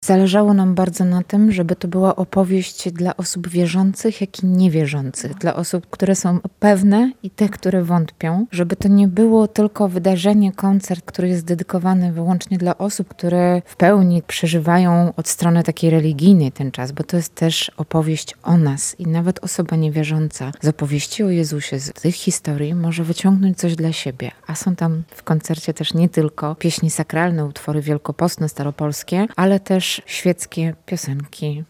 W Studiu im. Budki Suflera Radia Lublin specjalny koncert pasyjny
Jego program łączył repertuar średniowieczny, renesansowy, ludowy i współczesny. Zabrzmiały: fragment „Lamentu świętokrzyskiego”, ludowe pieśni pasyjne, żałobne i lamentacyjne, piosenki podejmujące temat przemijania, grzechu i ludzkiej słabości.